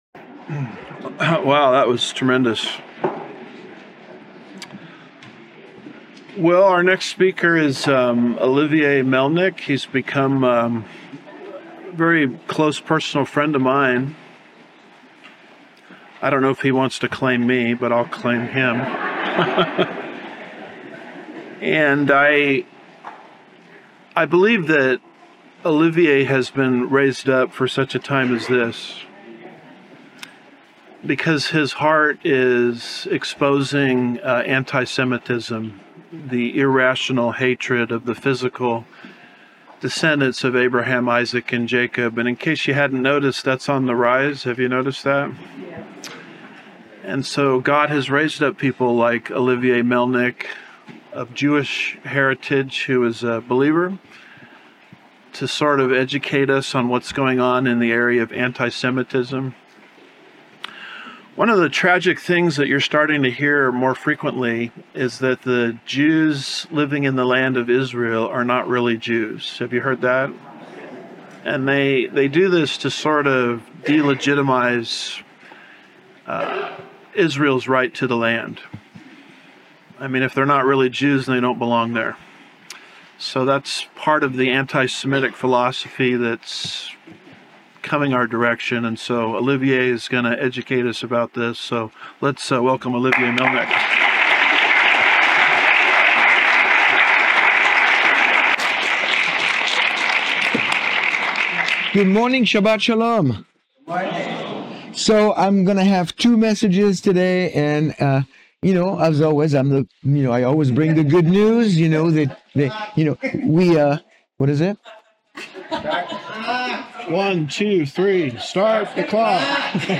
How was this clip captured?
2025 Prophecy Conference